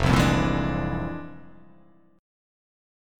FmM13 chord